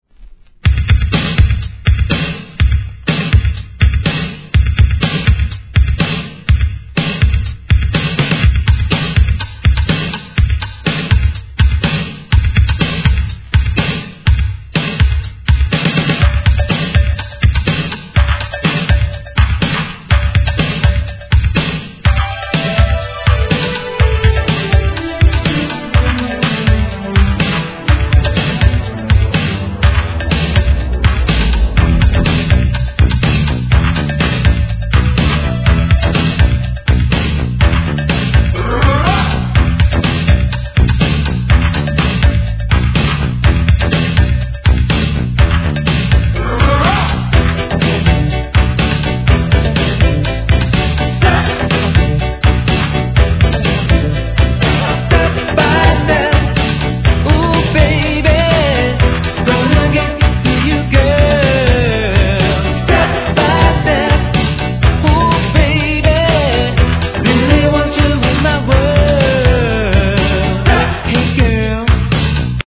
SOUL/FUNK/etc...